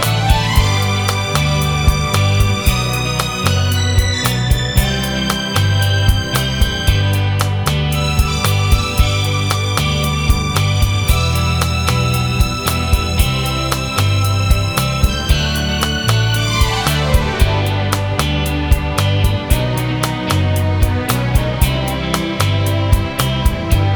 Two Semitones Down Pop (1960s) 2:11 Buy £1.50